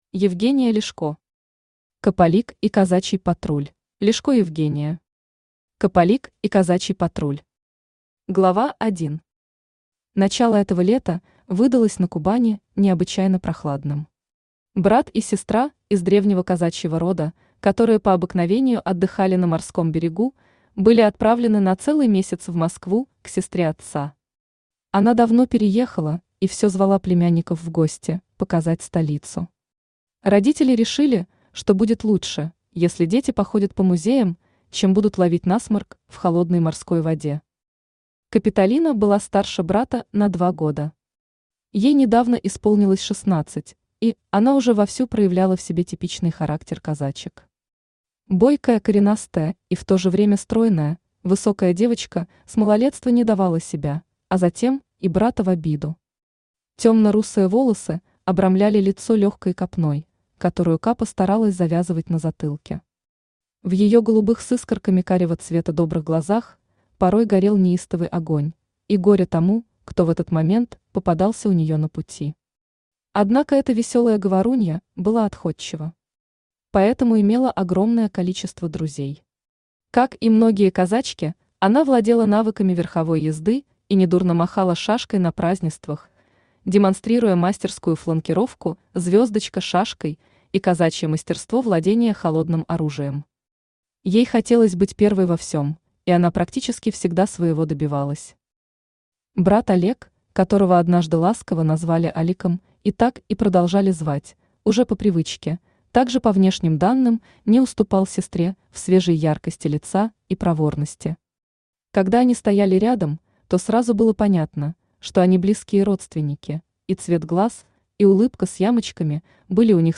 Аудиокнига Капалик и казачий патруль | Библиотека аудиокниг
Aудиокнига Капалик и казачий патруль Автор Евгения Ляшко Читает аудиокнигу Авточтец ЛитРес.